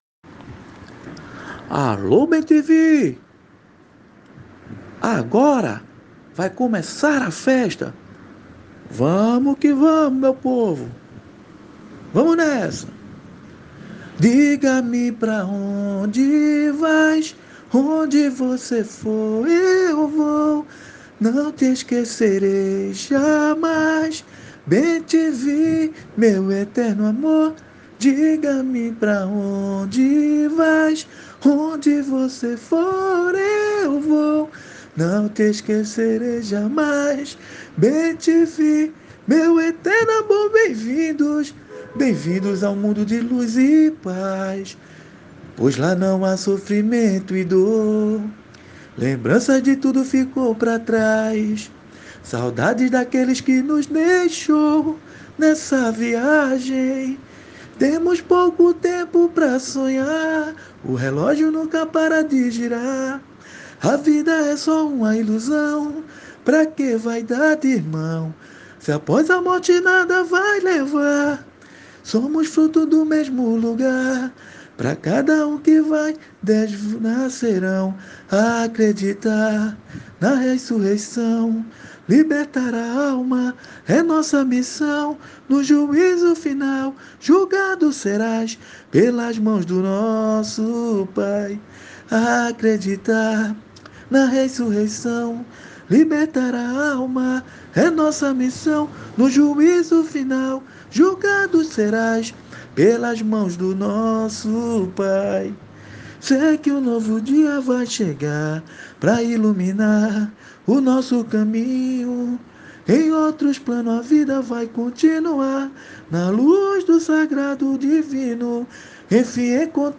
Samba  03 - SAMBA CAMPEÃO